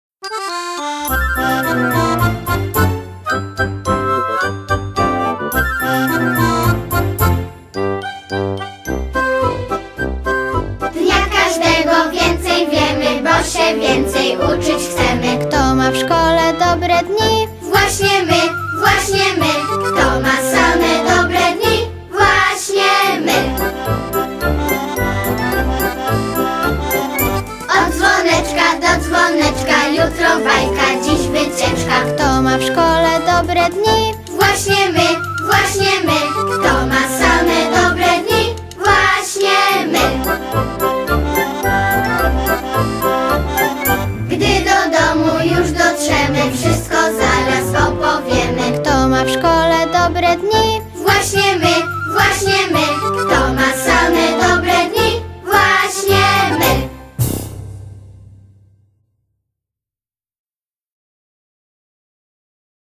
Właśnie my (wersja wokalno-instrumentalna)